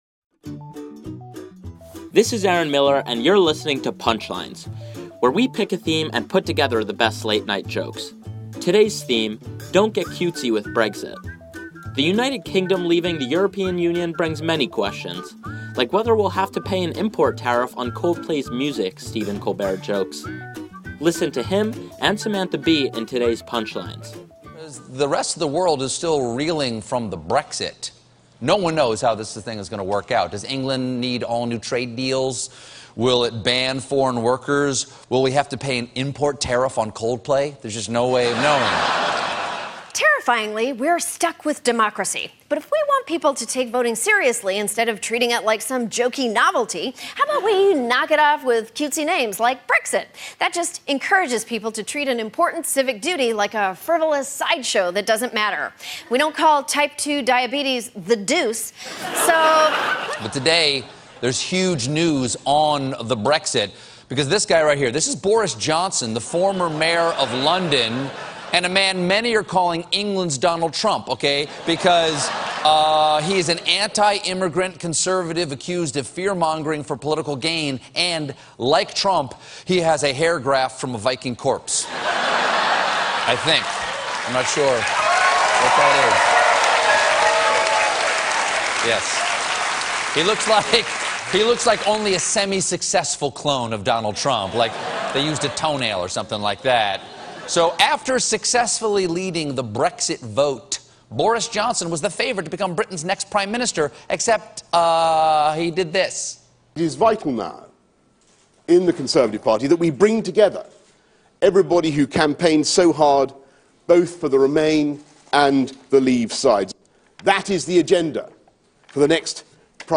The late-night comics take a look at the name Brexit and its continued aftermath.